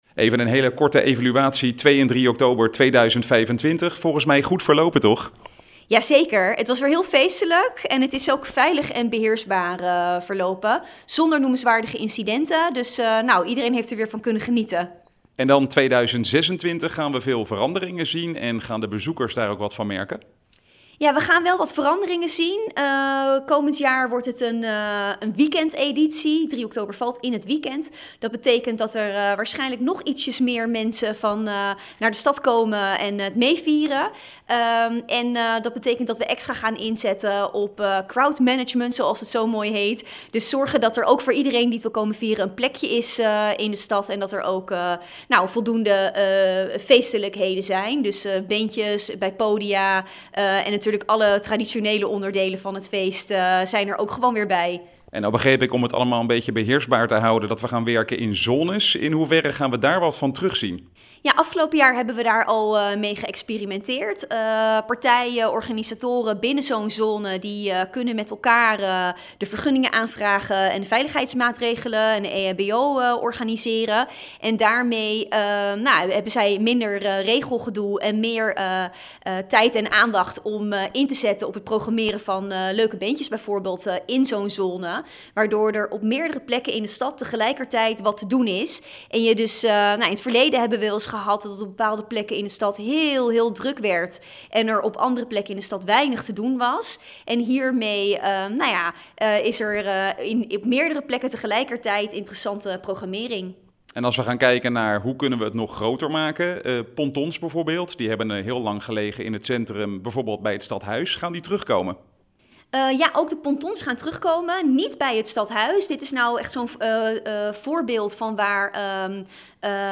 Verslaggever
in gesprek met wethouder Prescillia van Noort over Leidens Ontzet: